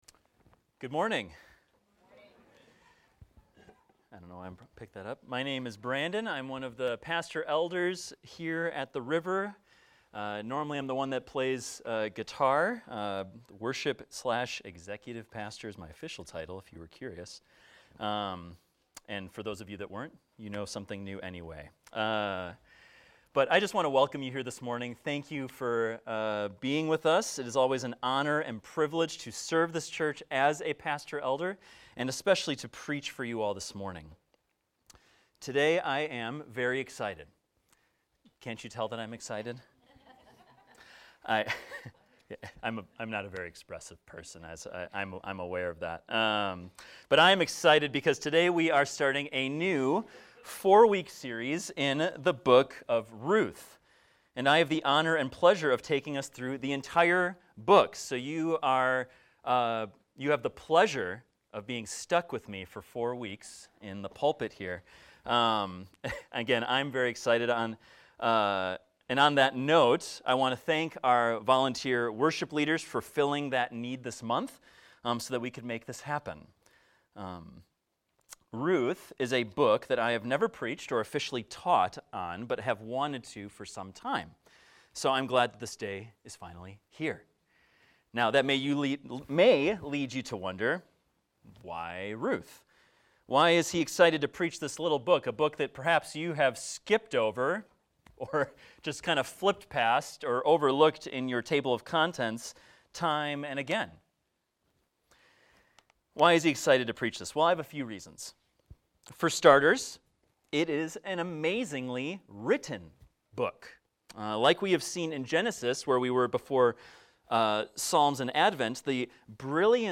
This post is a sermon on Ruth 1 titled "Coming Up Empty"